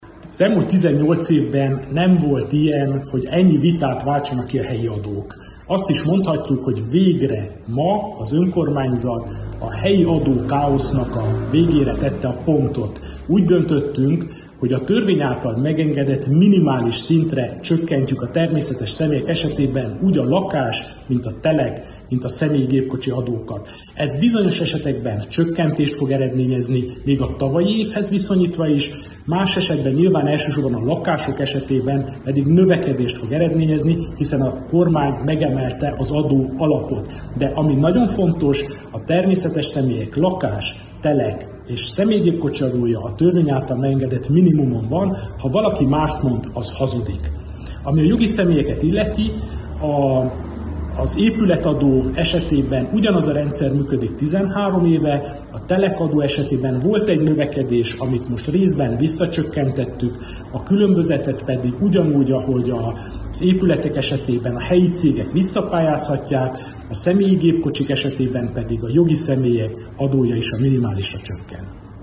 Antal Árpád polgármester sajtótájékoztatón hangsúlyozta: Sepsiszentgyörgyön még soha nem fordult elő, hogy minden, magánszemélyekre vonatkozó helyi adó a törvényes minimumon legyen.